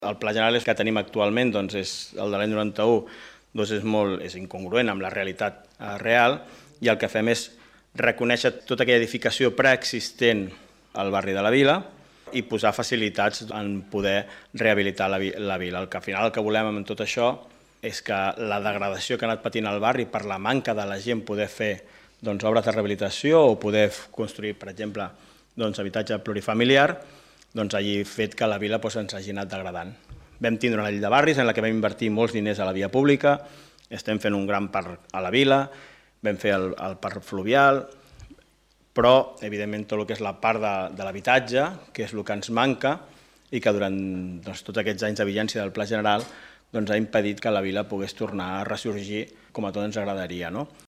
L’Ajuntament de Martorell va aprovar, en el Ple Ordinari d’ahir a la nit, una modificació puntual del Pla General d’Ordenació Urbana (PGOU) de Martorell de gran rellevància per La Vila.
Albert Fernández, regidor Planificació Urbanística